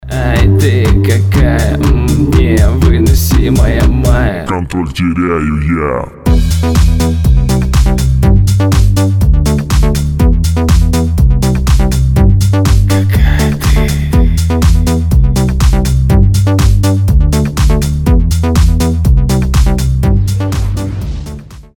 • Качество: 320, Stereo
мужской вокал
dance
club
клубная музыка
Занятный клубнячок